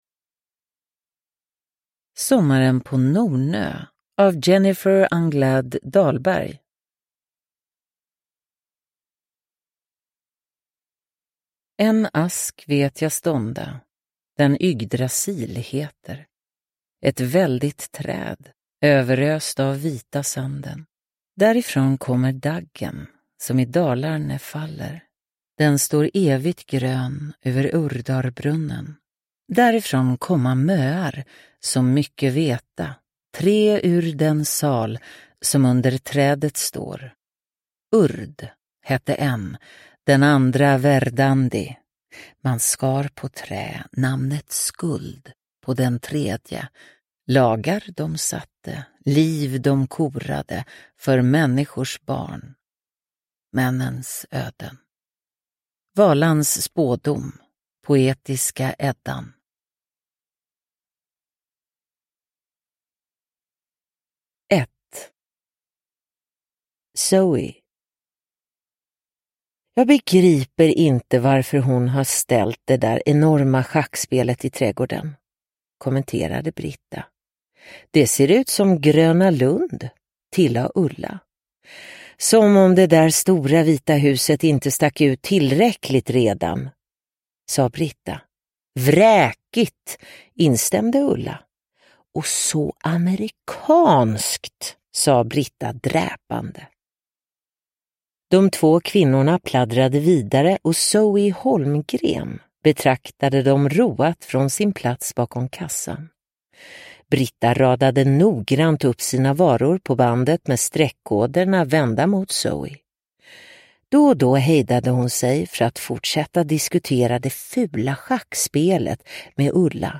Sommaren på Nornö – Ljudbok – Laddas ner